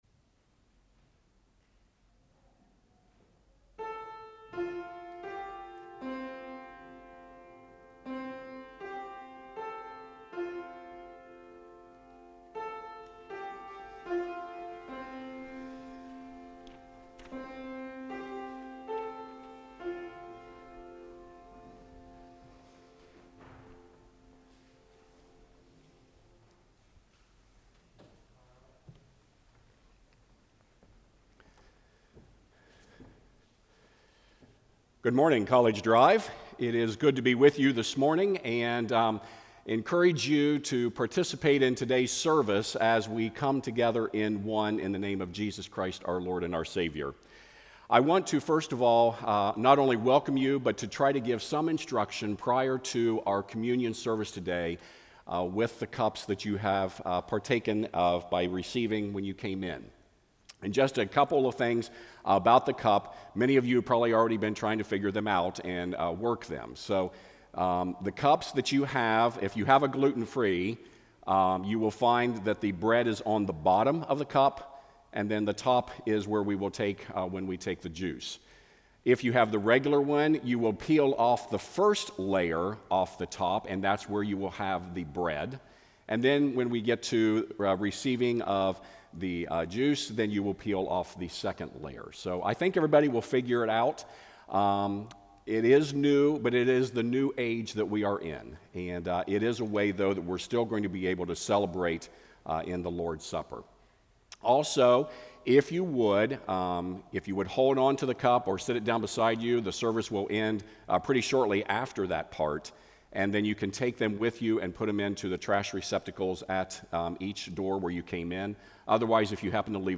CDPC-10.4.20-Worship-Service.mp3